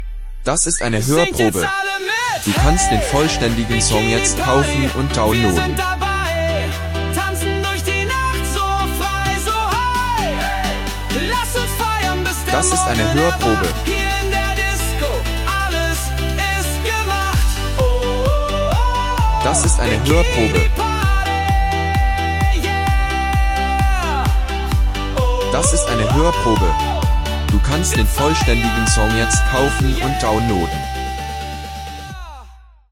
im unverwechselbaren Ballermann-Style!